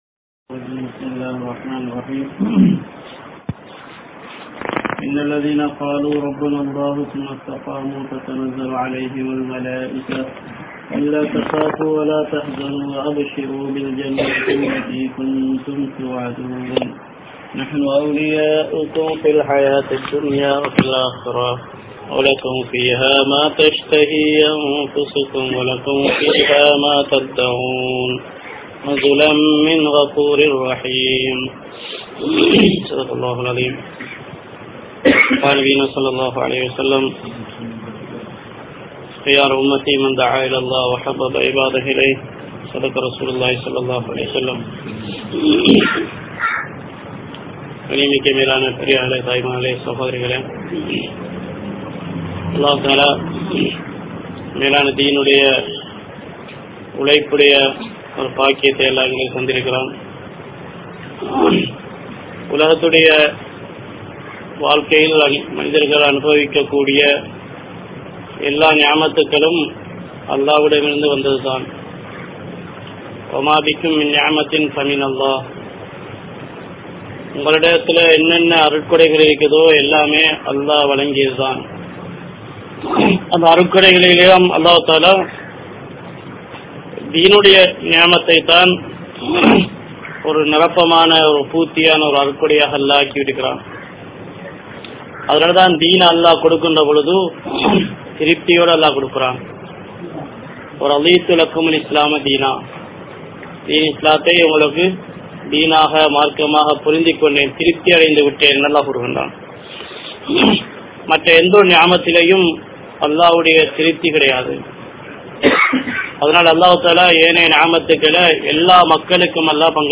Eattru Konda Dheenai Vilanki Kolvoam (ஏற்றுக்கொண்ட தீணை விளங்கிக் கொள்வோம்) | Audio Bayans | All Ceylon Muslim Youth Community | Addalaichenai